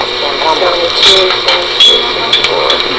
EVP's
We were investigating a local home but we were outside at a neighbors house when we got these.